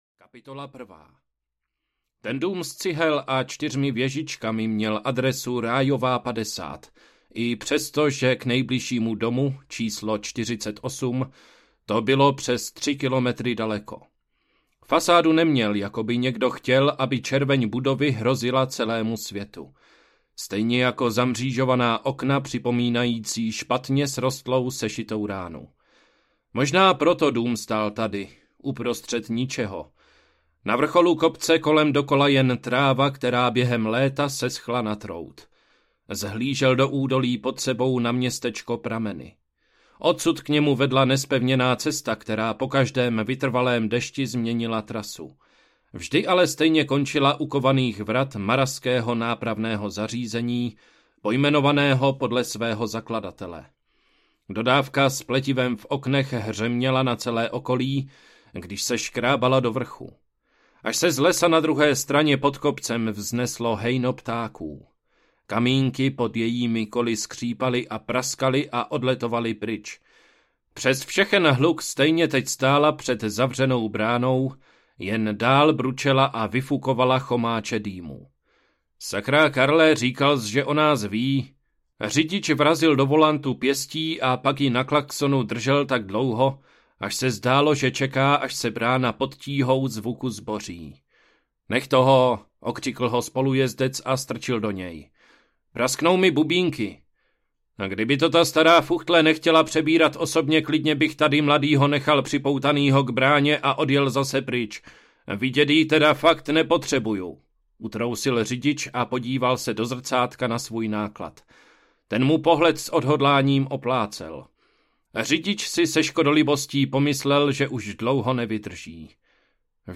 Zrození vrány audiokniha
Ukázka z knihy